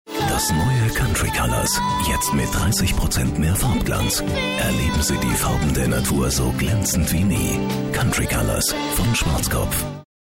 Gänsehautstimme für Werbung, Imagefilm, Station Voice uvm.
Kein Dialekt
Sprechprobe: Industrie (Muttersprache):